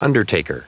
Prononciation du mot undertaker en anglais (fichier audio)